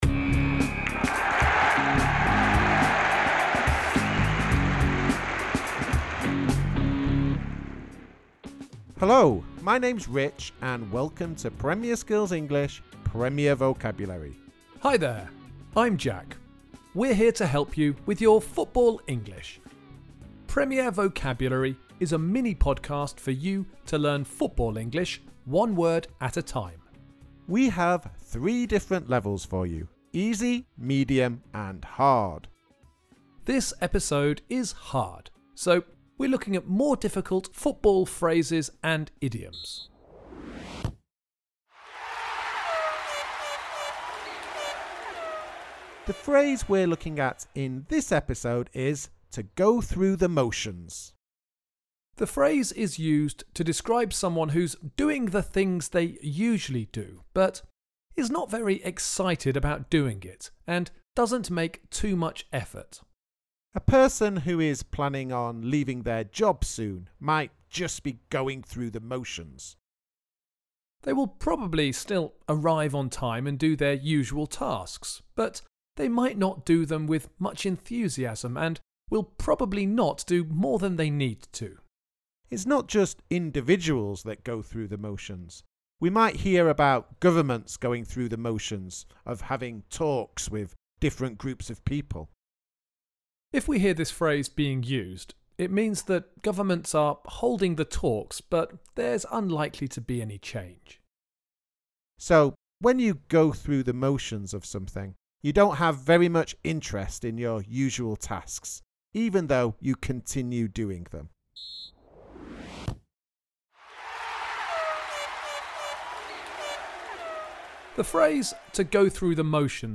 Premier Vocabulary is a mini-podcast for you to learn football English one word at a time.